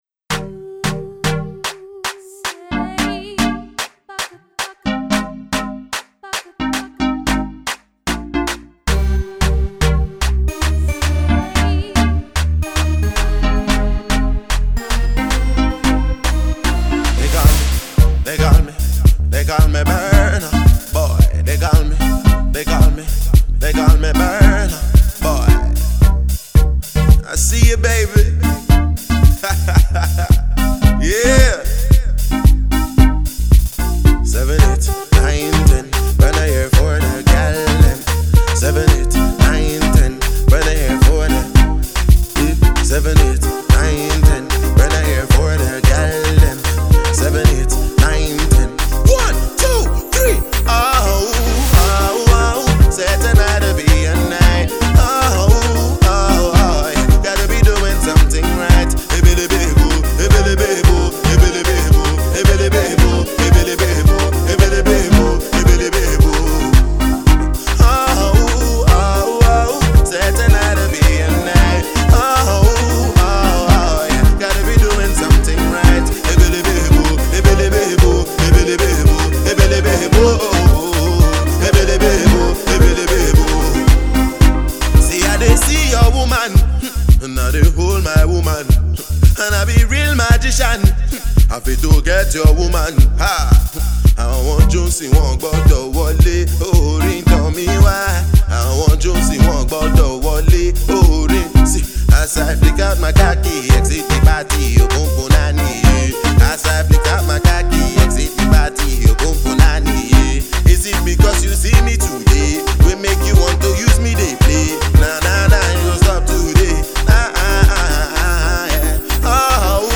extreme catchiness and unique vibe